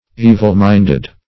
Evil-minded \E"vil-mind`ed\, a.